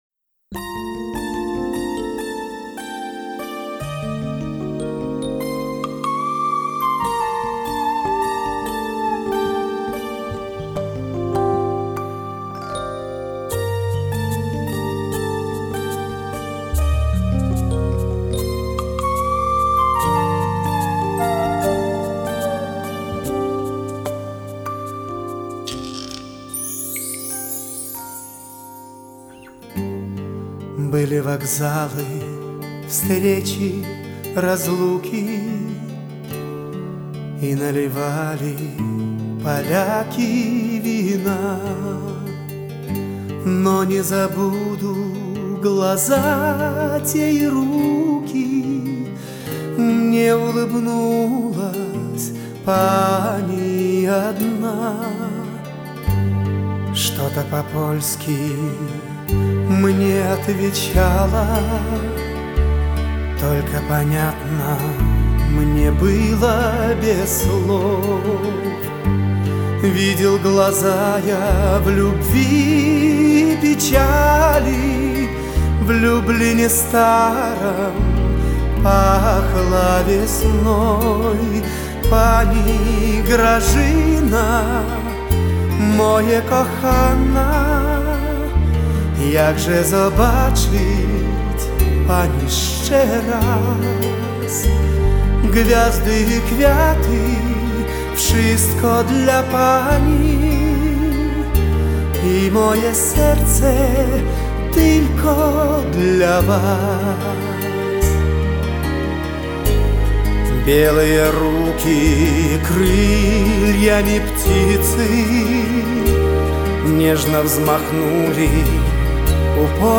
Как нежная колыбельная на ночь.